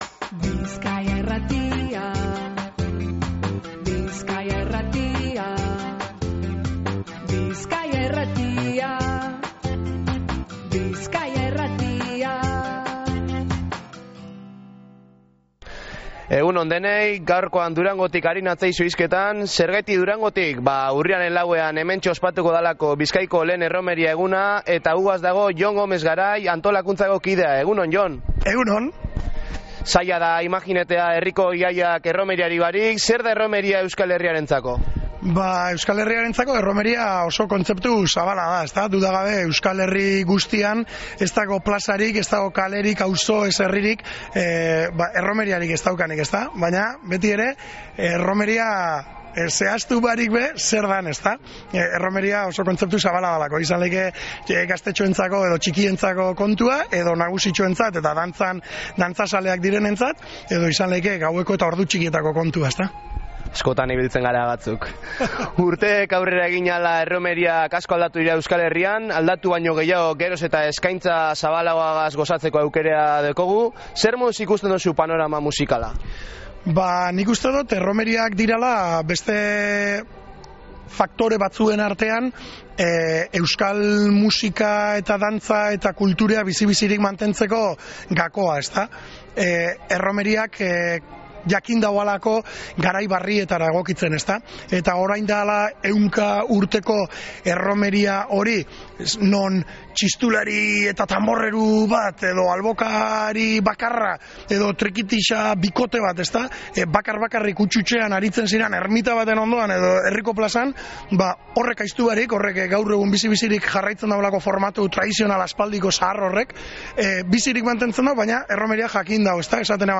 Azken hau alkarrizketatu genduan egitasmoaren inguruko ikuspegia ezagutzeko.